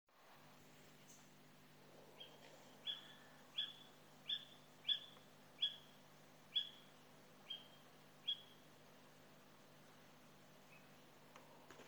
cris animaux nocturne
j'ai fais un enregistrement sur lequel on entend un cri au d�but de l'enregistrement, puis un autre cri diff�rent du premier en deuxi�me partie d'enregistrement.